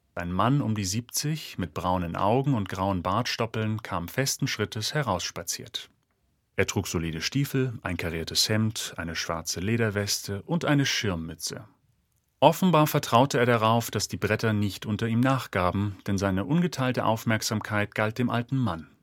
vielseitig, vertrauensvoll, sonor, jung, warm, markant, ausdrucksstark, authentisch, seriös, werbend, humorvoll, wiedererkennbar
Sprechprobe: Industrie (Muttersprache):